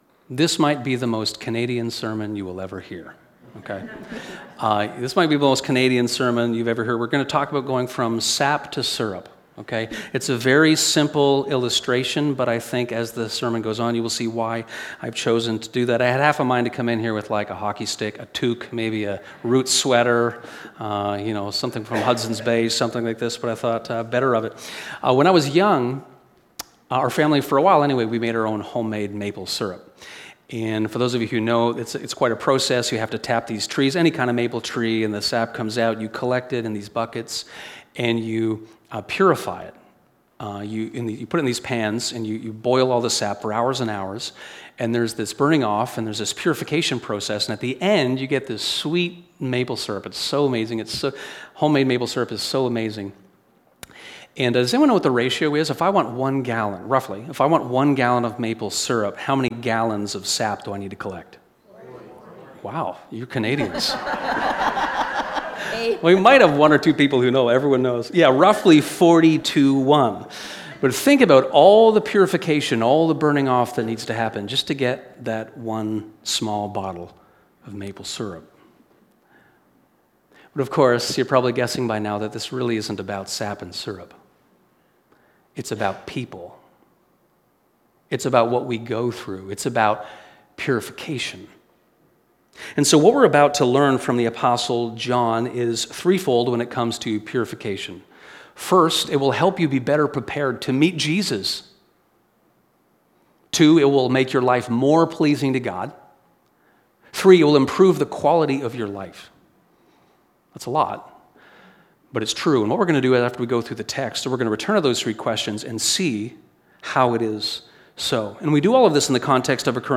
This sermon on 1 John 2:28 - 3:10 explores this topic and provides encouragement forward for those stuck in sinful patterns, and also two practical steps in the process of Christlike purification.